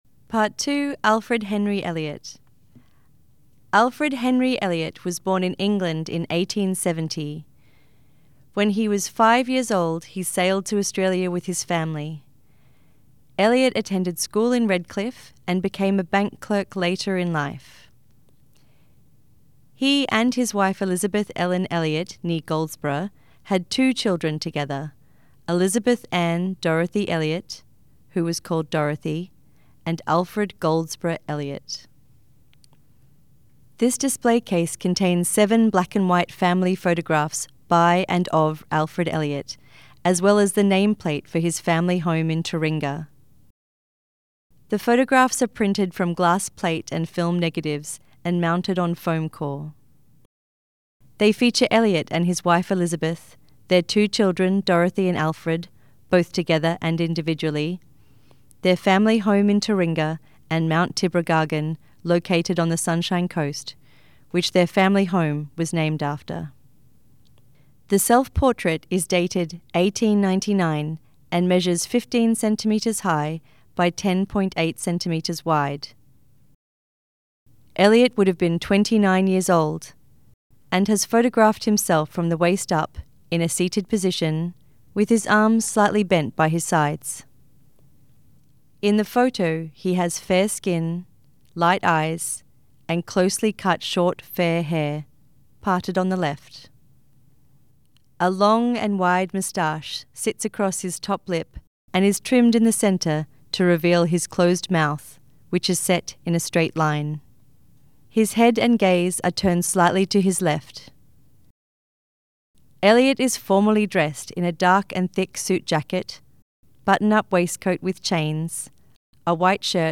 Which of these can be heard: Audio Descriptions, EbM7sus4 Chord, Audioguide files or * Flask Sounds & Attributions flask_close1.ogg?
Audio Descriptions